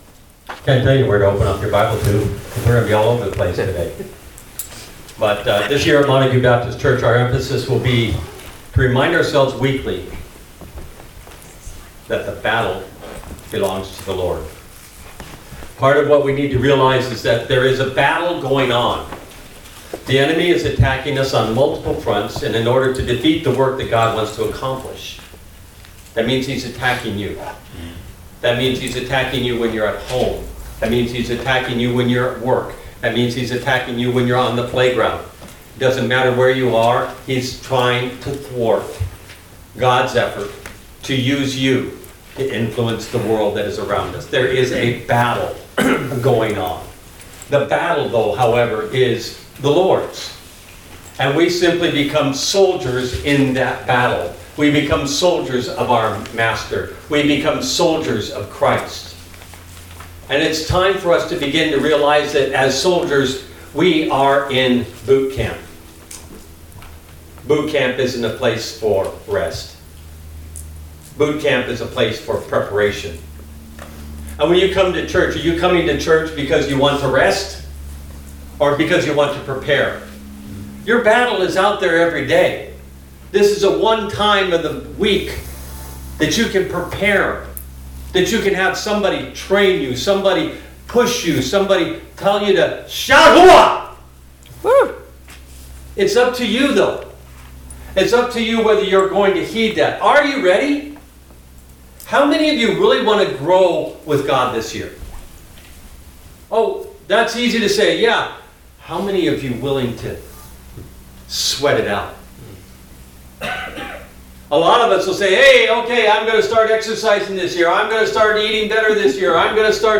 All Sermons Giving Your All Deuteronomy 6:5 7 January 2024 Series